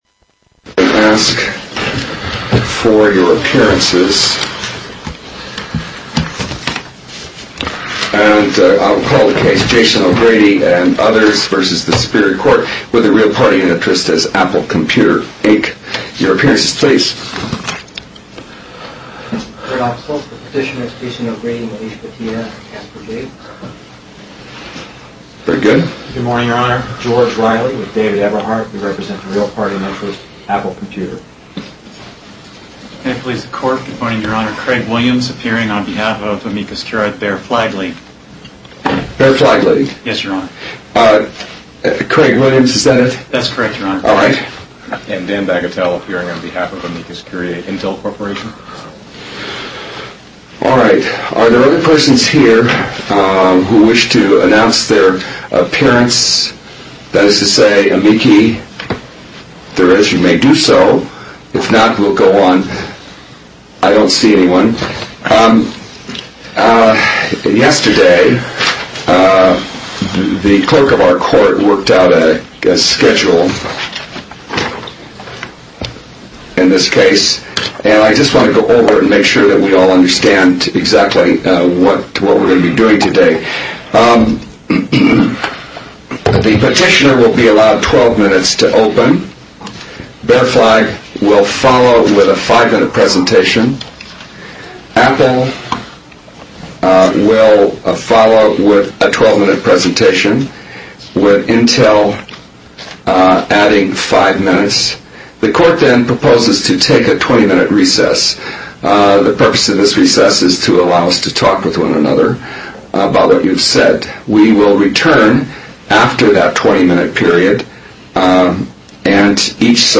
apple-v-does-appellate-argument-32k.mp3